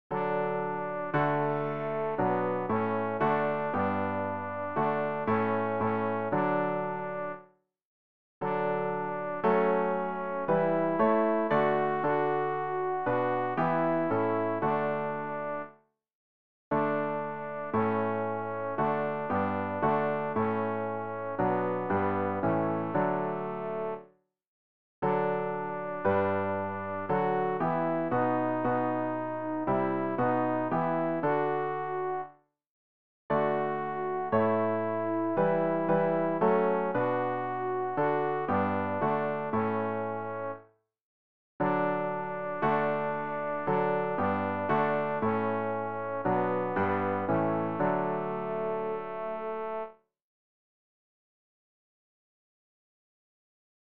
alt-rg-033-singt-mit-froher-stimm-voelker-jauchzet-ihm.mp3